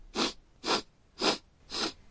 Descarga de Sonidos mp3 Gratis: snif olfatear 1.
snif-5.mp3